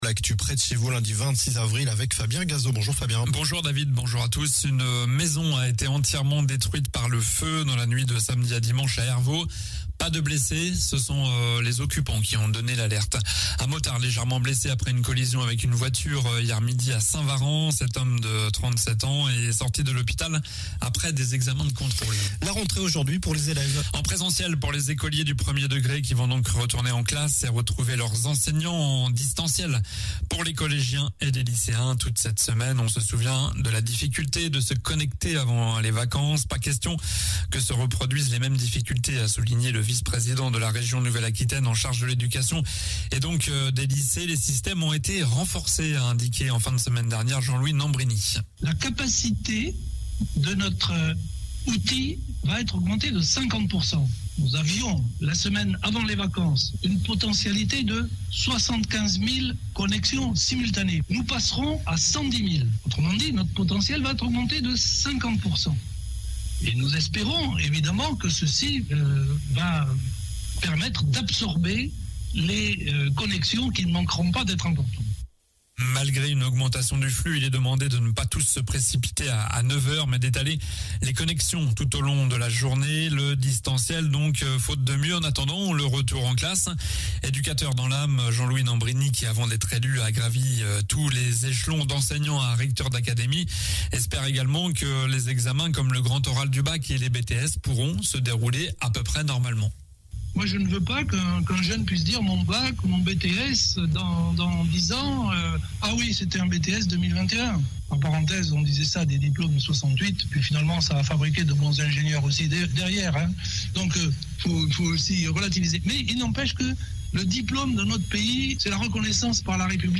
JOURNAL DU LUNDI 26 AVRIL (MIDI)